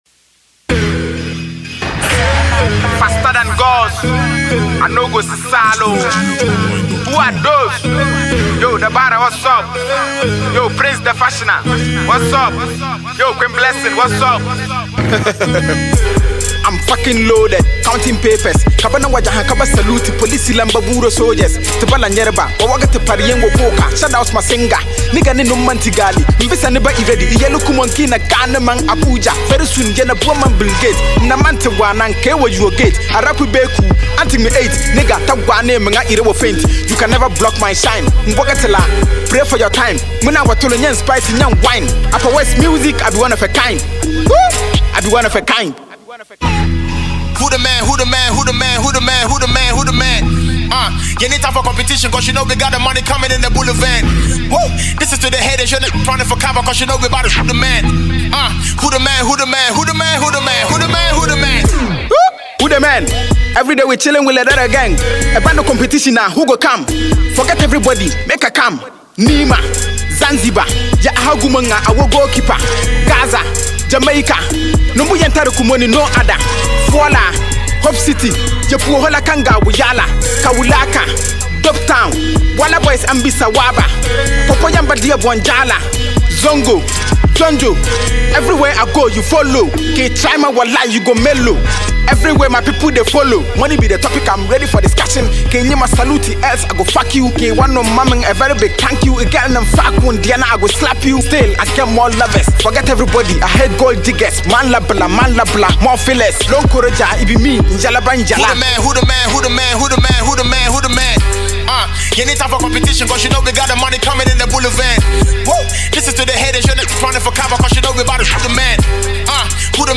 rapper
hiphop bar for bar jam